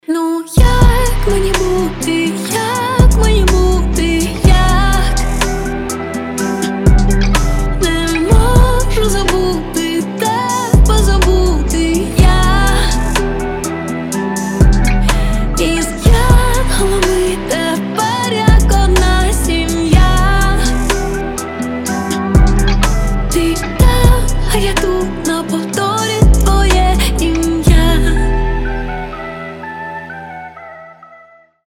• Качество: 320, Stereo
медленные
красивый женский вокал